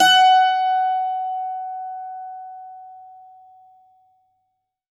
STRINGED INSTRUMENTS
52-str14-bouz-f#4.wav